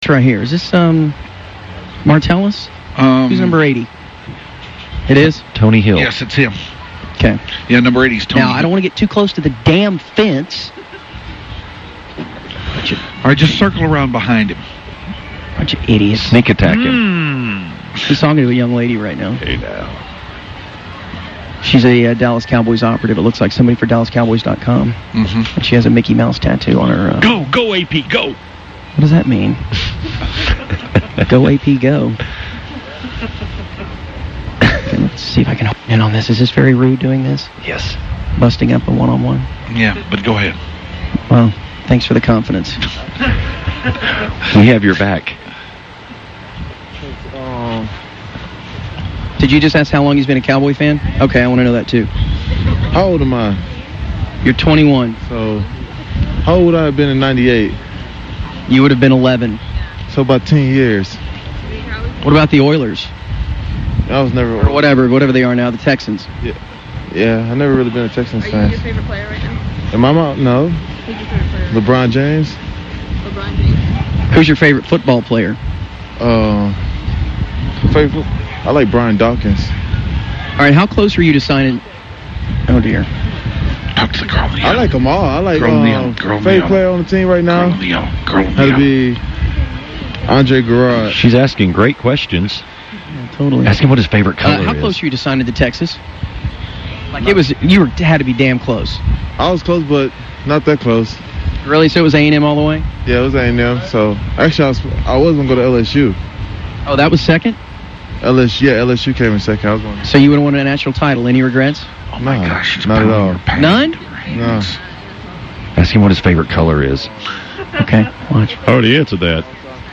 martellus-bennett-interview.mp3